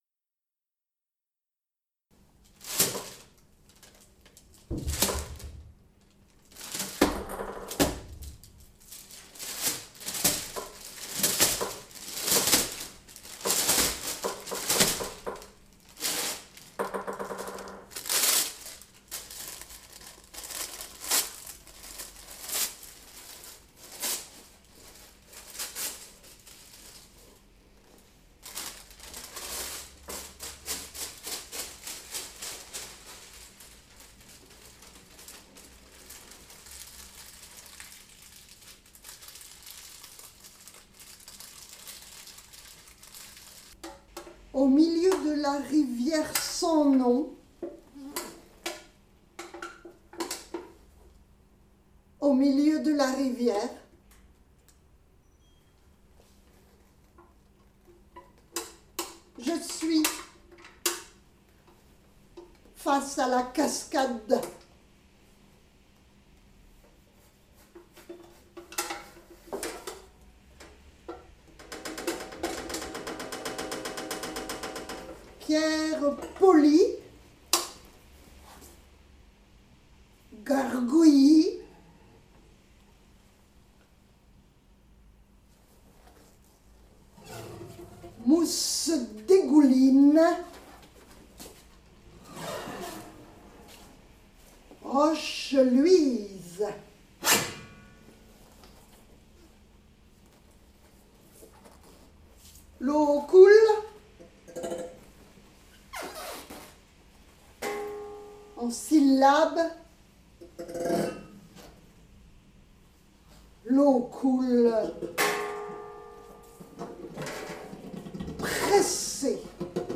PoésiC en duo #1 | improvisation